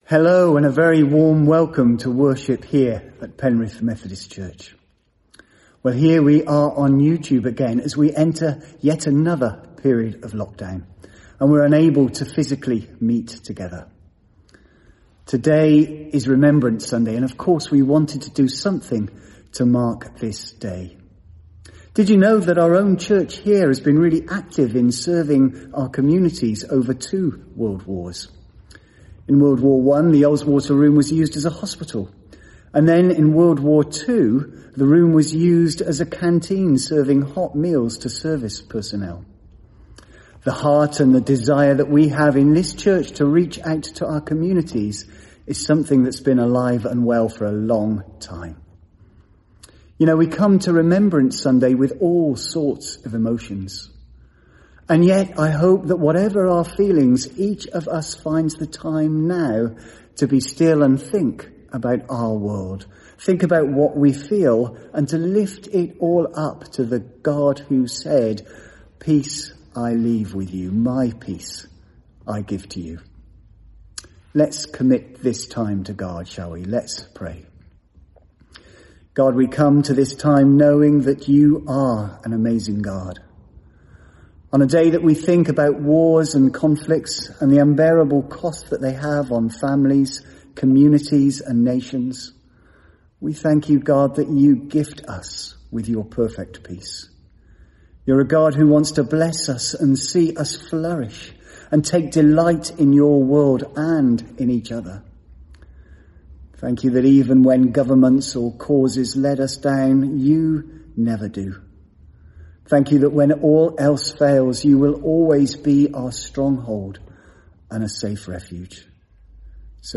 A message from the series
From Service: "10.30am Service"